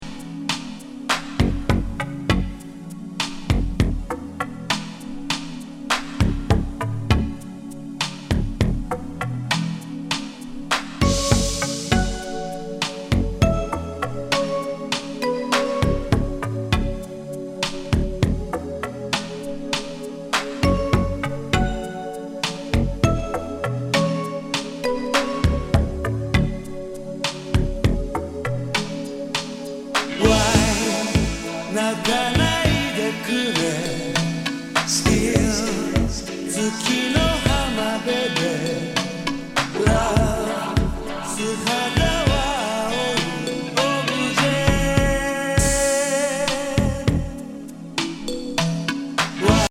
メロウ・バレアリック